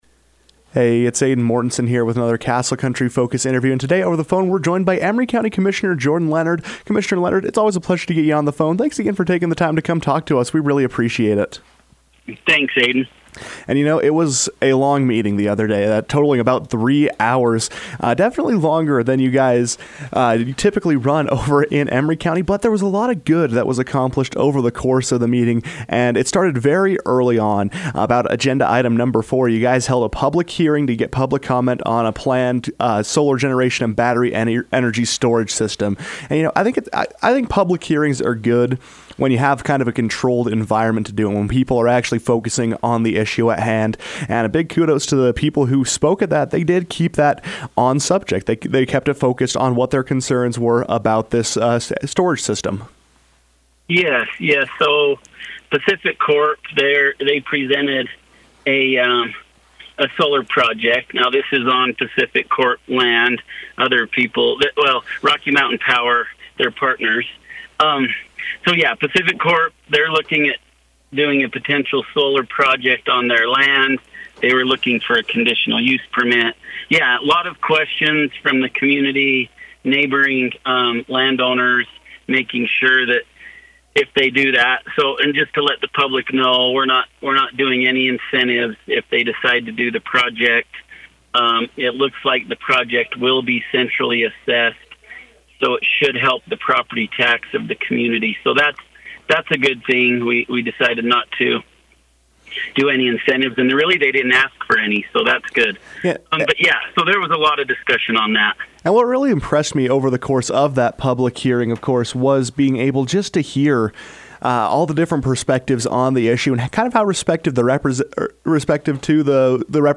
Following the biweekly meeting of the Emery County Commissioners, Commissioner Jordan Leonard joined the KOAL newsroom to discuss what's happening along the San Rafael Swell.